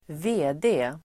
Ladda ner uttalet
VD förkortning, MD Uttal: [²v'e:de:] Definition: verkställande direktör; chef för ett företag (managing director, (president [US]), chief executive officer, CEO) Förklaring: Utses av styrelsen i ett aktiebolag.